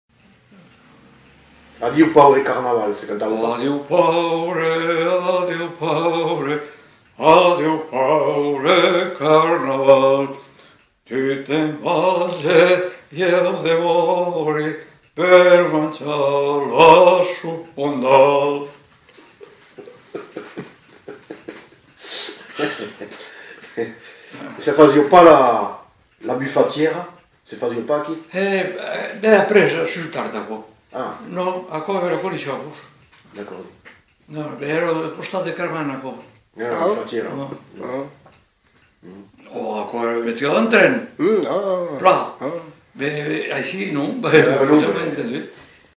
Aire culturelle : Lauragais
Genre : chant
Effectif : 1
Type de voix : voix d'homme
Production du son : chanté
Classification : chanson de carnaval